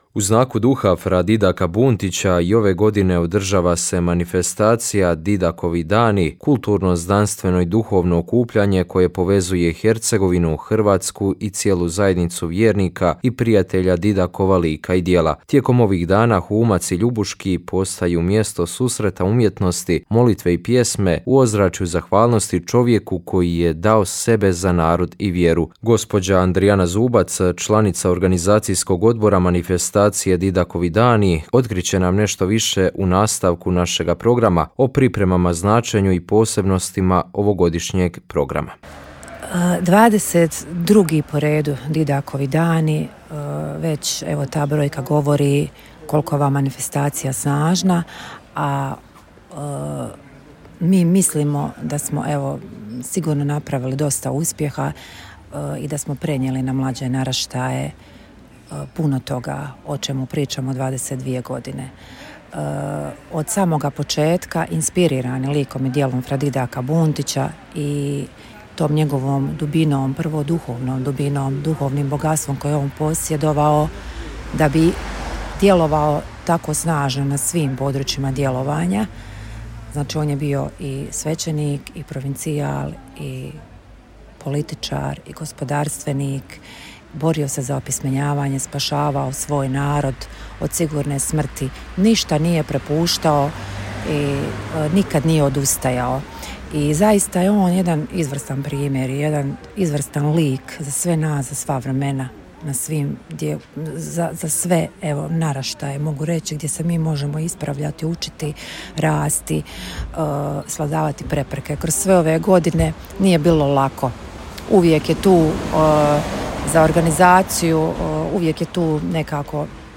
22. Didakovi dani otvoreni na Humcu – Akademija, koncert, dodjela priznanja zaslužnima… - Radio Mir
Didakove dane svečano je otvorio uzoriti kardinal Vinko Puljić, nadbiskup metropolit vrhbosanski u miru, naglašavajući važnost očuvanja nacionalnog identiteta i temeljnih vrijednosti koje su oblikovale hrvatski narod. Pozvao je na hrabrost, ali ne onu koja napada druge, već onu koja potiče da budemo vjerni sebi, svojim korijenima i vlastitoj savjesti.